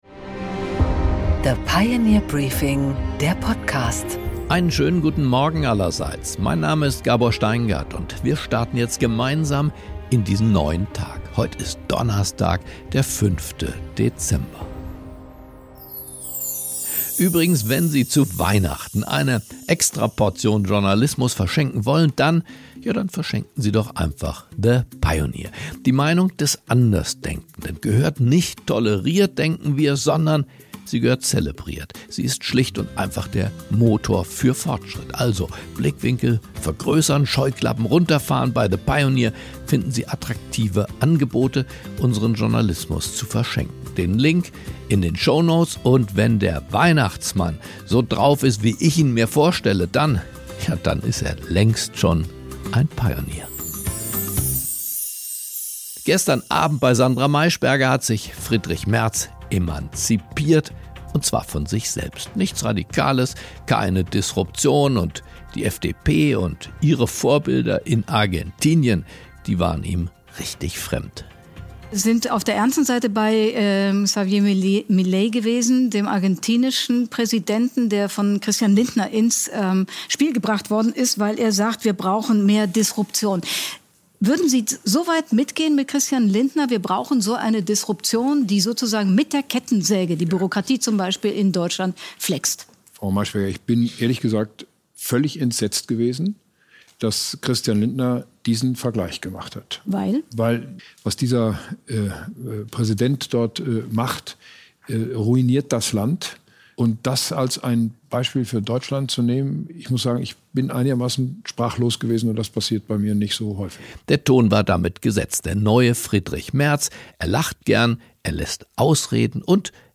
Gabor Steingart präsentiert das Pioneer Briefing
Im Interview: Joe Kaeser, Aufsichtsratsvorsitzender bei Siemens Energy und bei Daimler Truck, spricht mit Gabor Steingart beim “Klartext Express”-Auftritt in München über Elon Musk, Management und Moral.